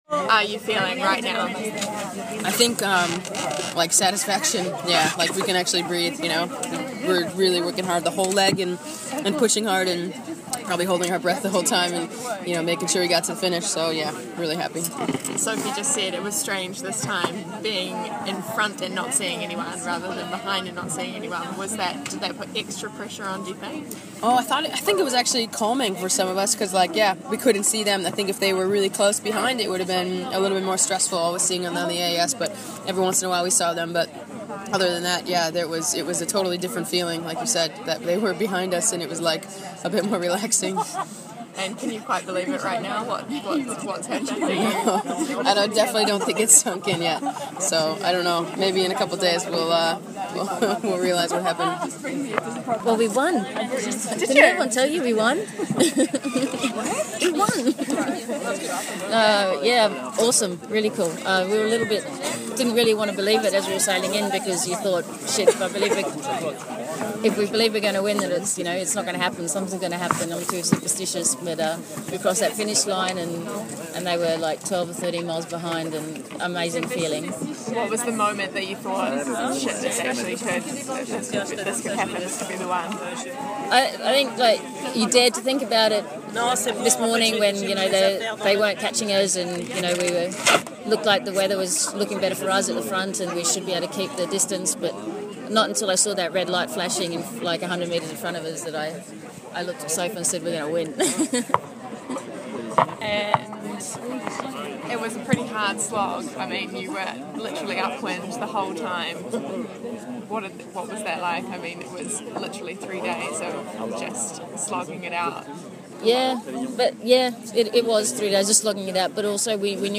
Team SCA_On Dock mix.mp3